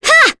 Selene-Vox_Attack5.wav